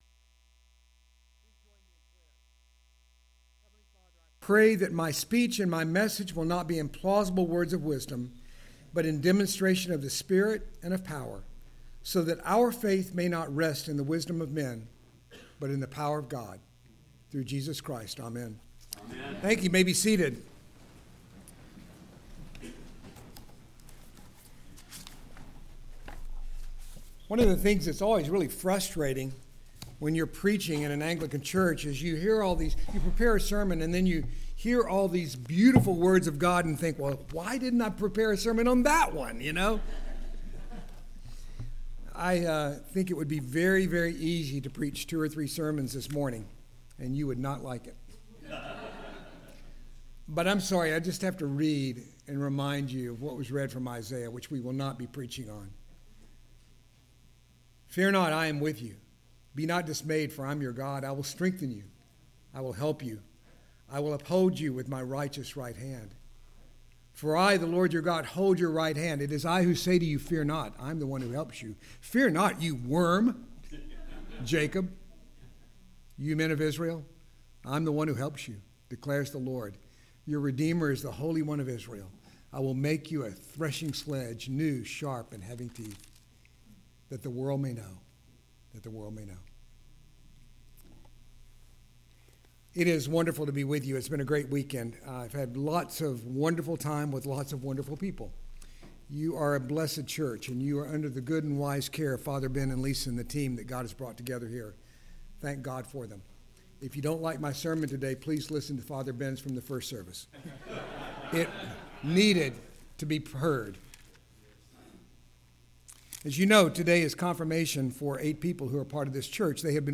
Confirmation Homily: Hebrews